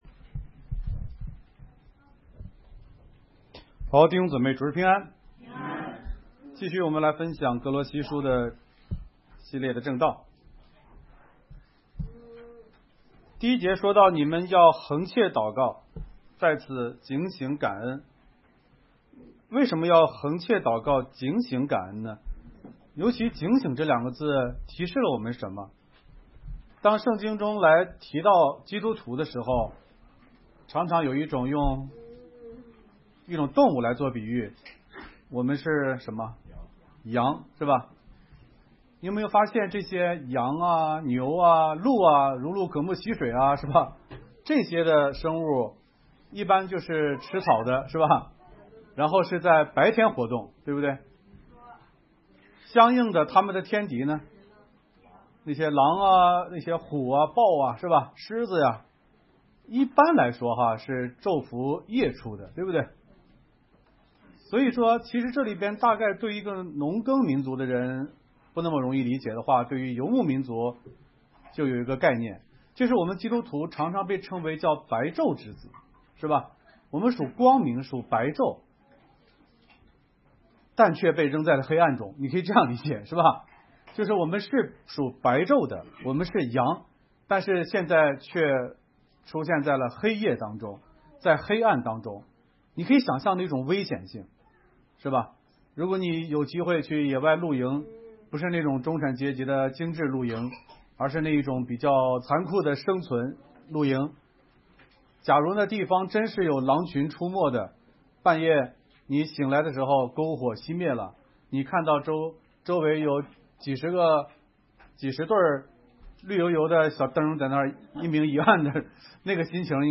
讲章